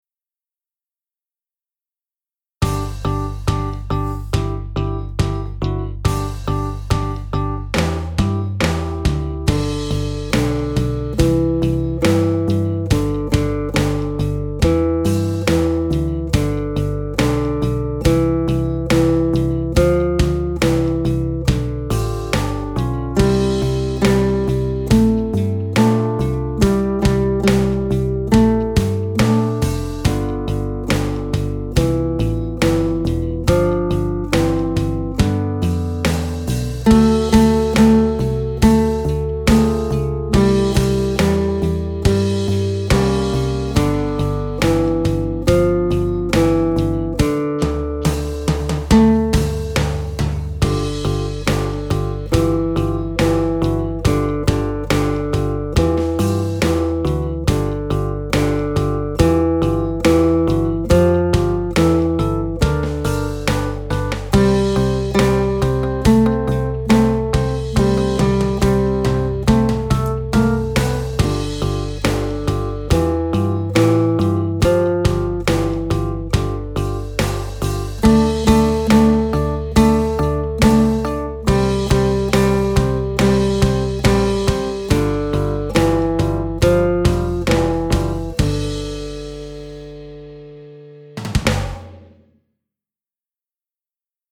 Version with accompaniment
Some of these mp3s are in mono.